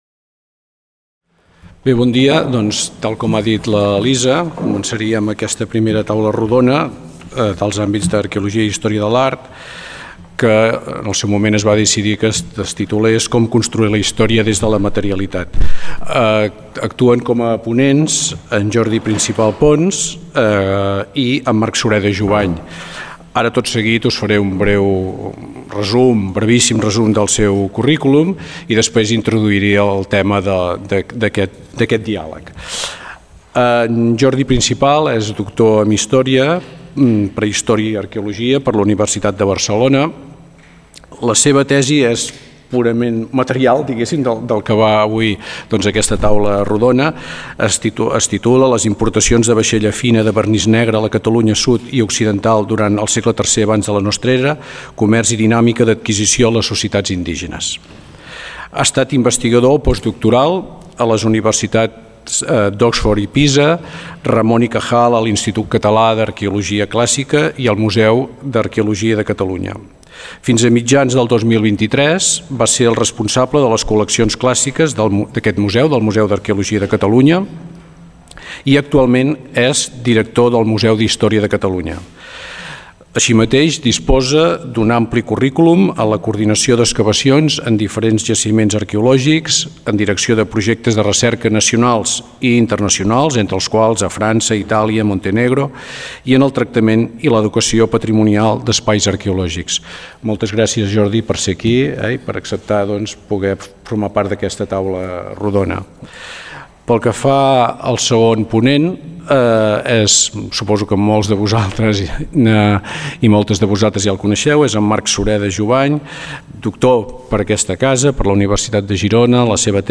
Primera taula rodona.
La ponència finalitza amb un debat entre els assistents i els ponents.